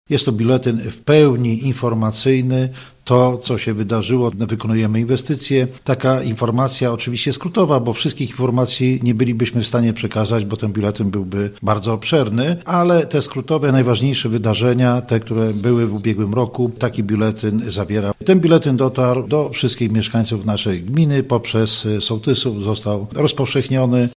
’- Dlatego uznaliśmy, że nasz biuletyn będzie łącznikiem samorządu z mieszkańcami – tłumaczy Czesław Kalbarczyk, wójt gminy Łagów.